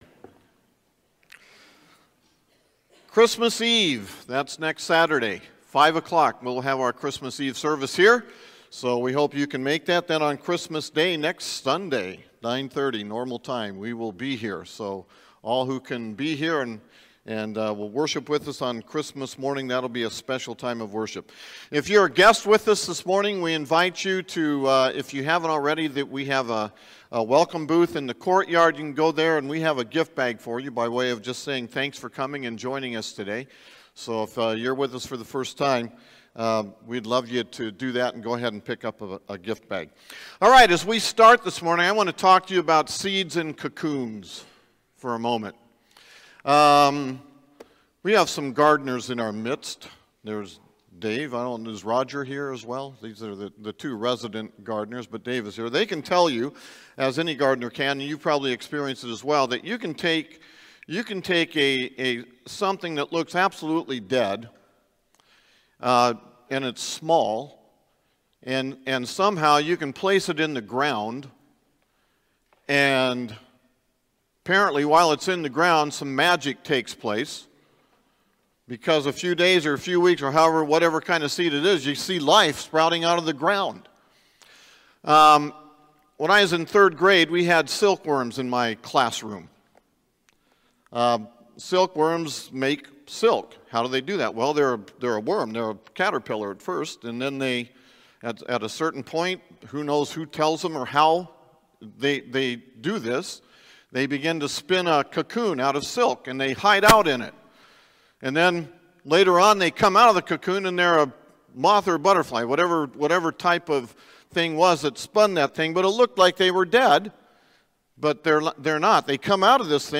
Sermons | Magnolia Baptist Church